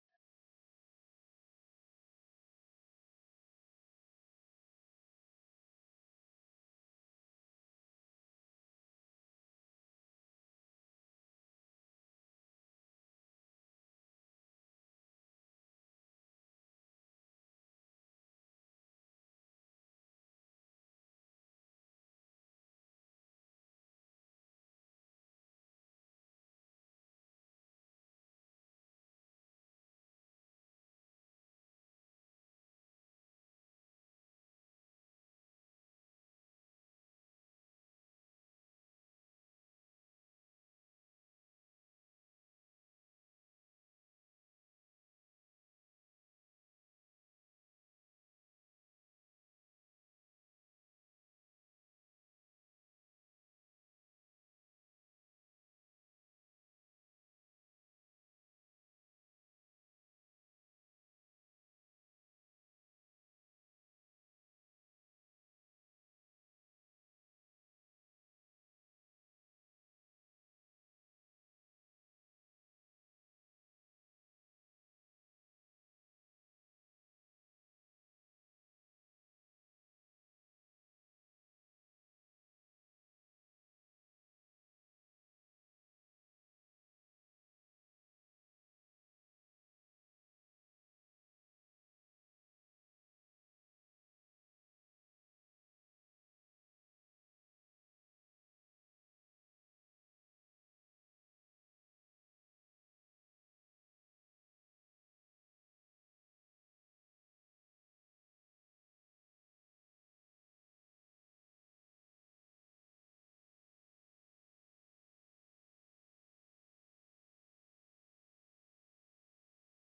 Keith Green addresses the dangers of grumbling and complaining among Christians, emphasizing that such attitudes reflect a lack of faith and trust in God. He draws parallels between the Israelites' desire to return to Egypt and modern believers' frustrations when faced with trials after accepting Christ.